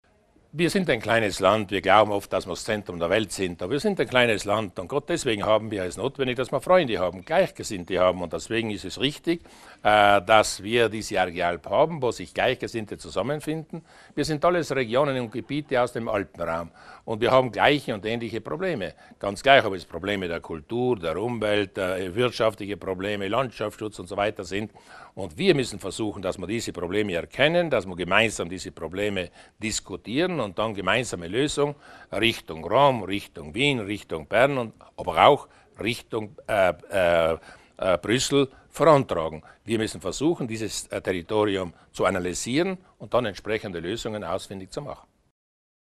Landeshauptmann Durnwalder zur Bedeutung der Arge Alp